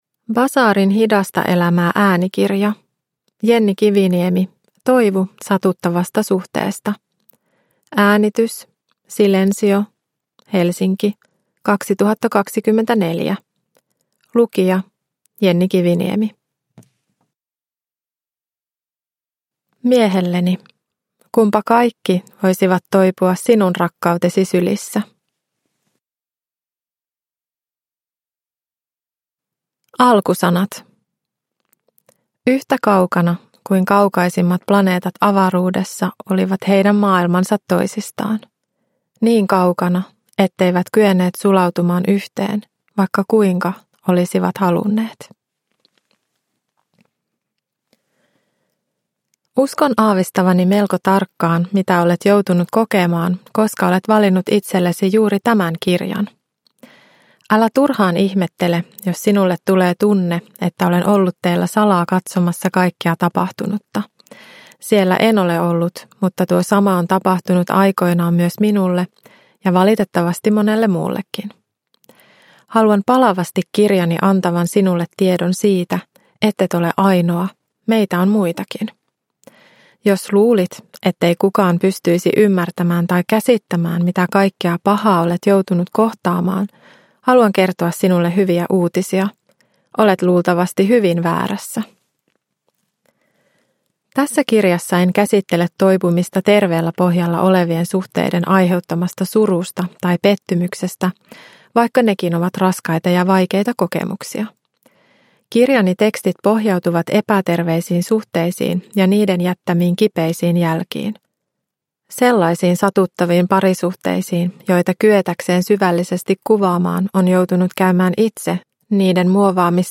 Toivu satuttavasta suhteesta – Ljudbok